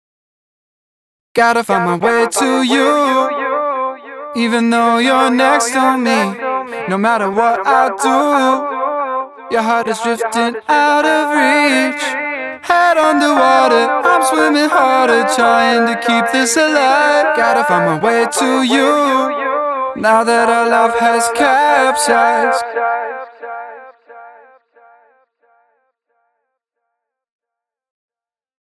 ボーカル空間系処理
「Aux 1」の「Stereo Delay」の後段にEQを挿し、オリジナルのボーカルを邪魔しないよう処理します。
Delay EQ
vocal-delay-eq.mp3